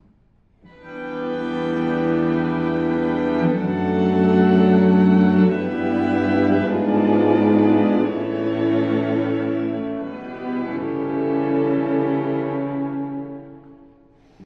美しく、どことなく神秘的な景色を思い浮かべる楽章です。
また、彼の後期バレエ音楽に認められるような、バイオリンの甘美な楽想も特徴的です。
教会のように神聖な響きもあります。